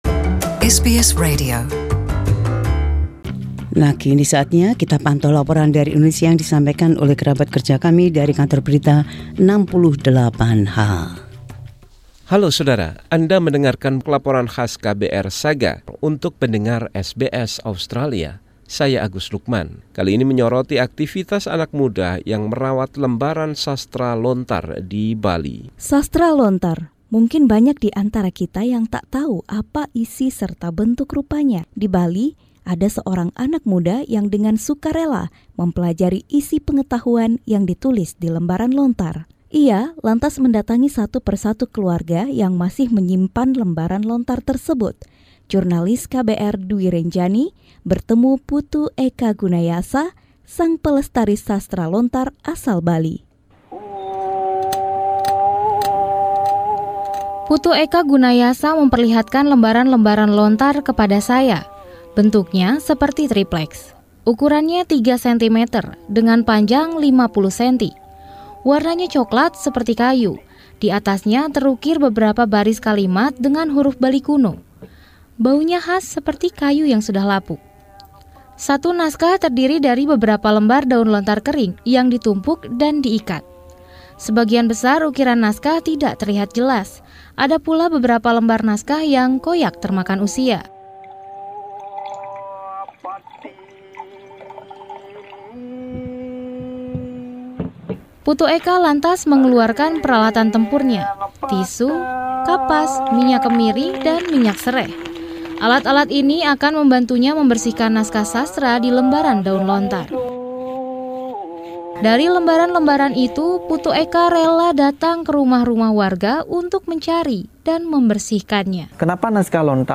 Laporan KBR 68H: Melestarikan Literature Lontar.